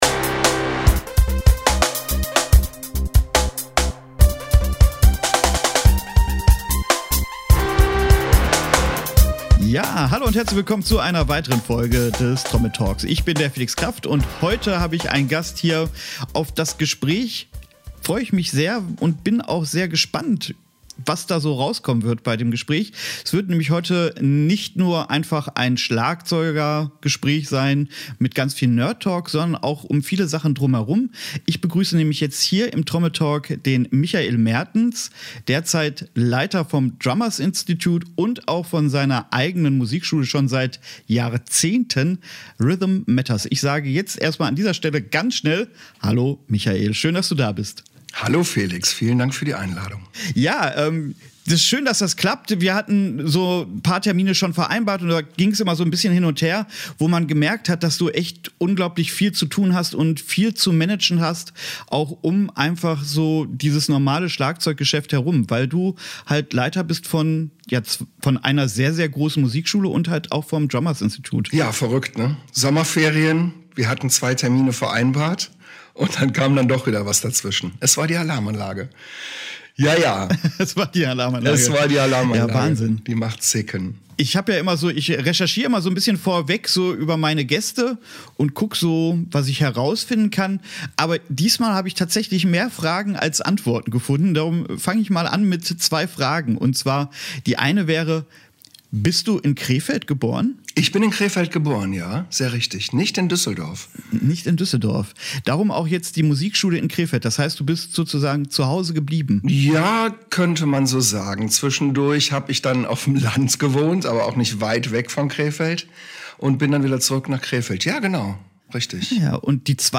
Was sich alles verändert hat, welche Schwierigkeiten und Herausforderungen es auf der Kreuzfahrt Musikschule und Drummers Institute gibt, erfahrt ihr in diesem Interview.